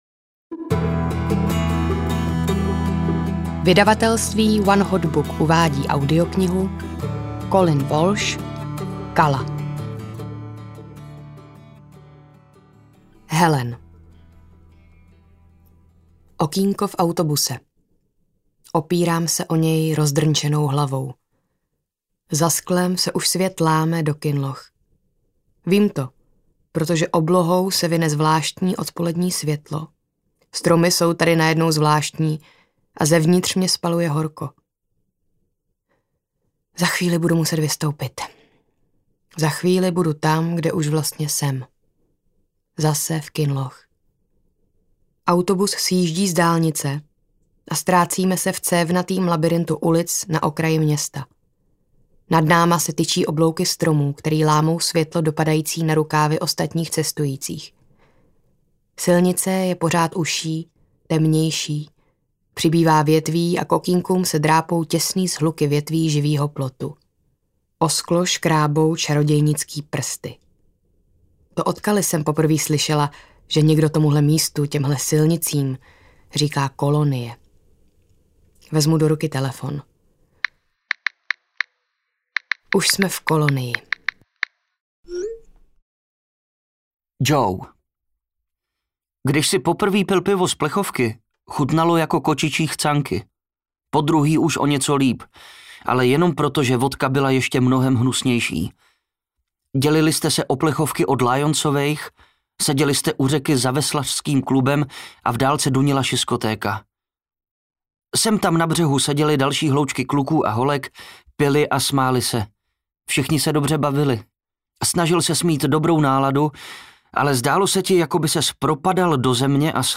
Kala audiokniha
Ukázka z knihy